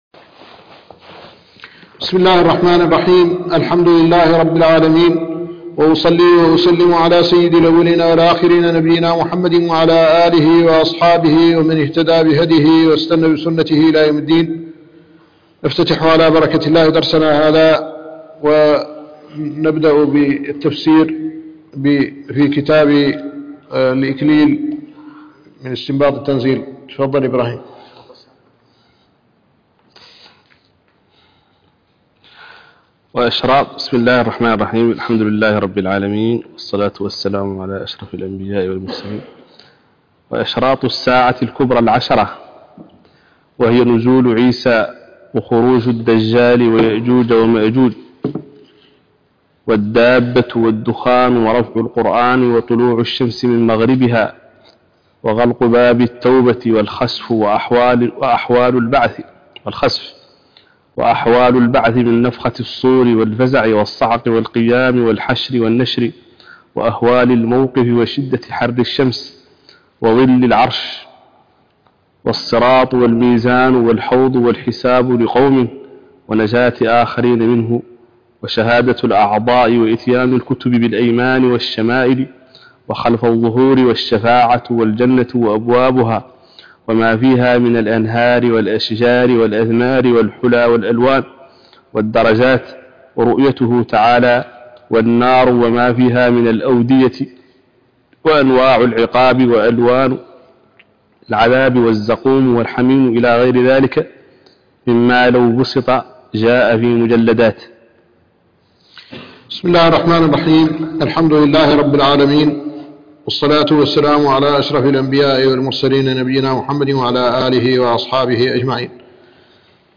الدرس الخامس عشر